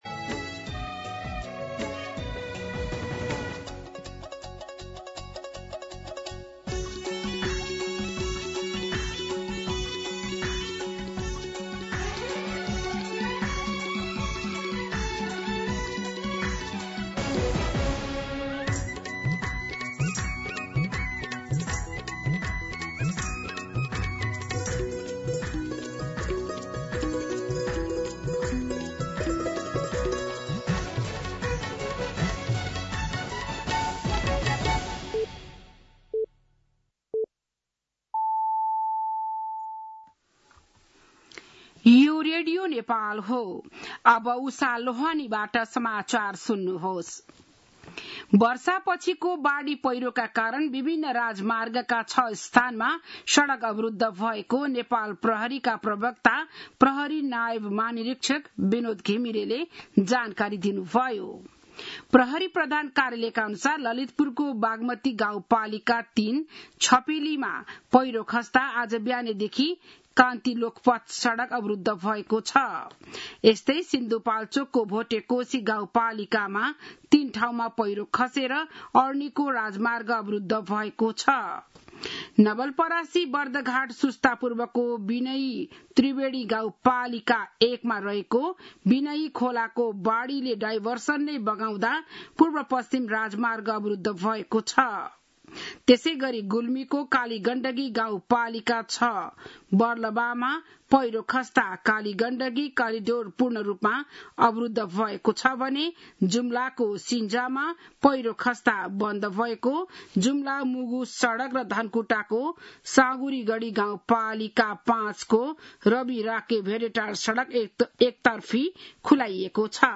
बिहान ११ बजेको नेपाली समाचार : ३ असार , २०८२